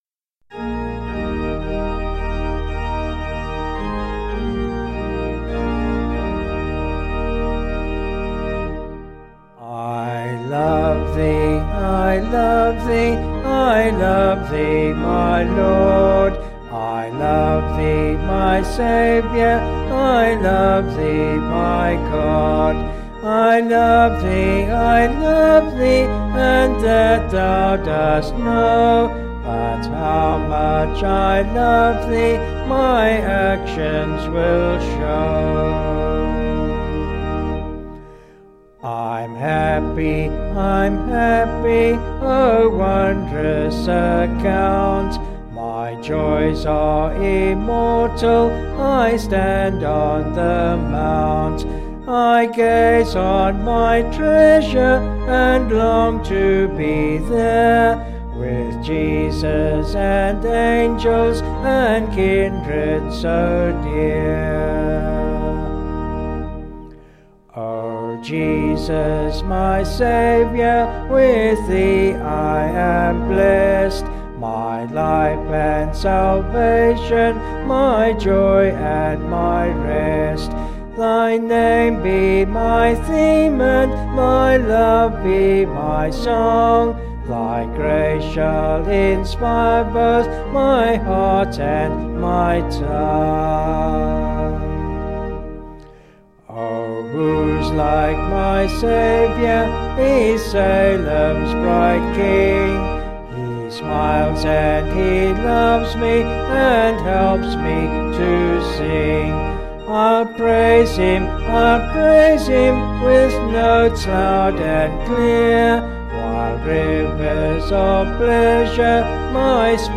(BH)   4/Eb
Vocals and Organ   264.3kb Sung Lyrics